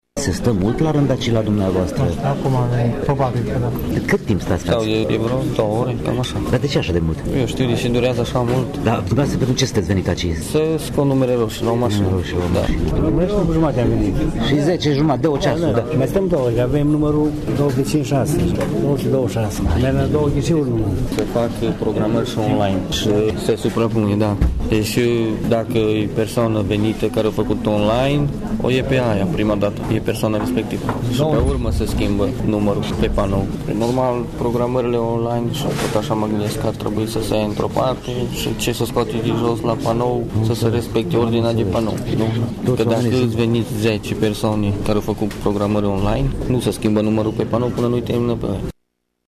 La etajul II al clădirii de pe strada Călărașilor din Tg. Mureș erau zeci de persoane, așteptând de mai bine de 2 ore să își preschimbe permisul.
Unii spun că nu e în regulă ca cei ce se programează online să fie favorizați și să intre în fața celor care și-au luat număr de ordine direct de la sediul Serviciul de înmatriculări auto Mureș: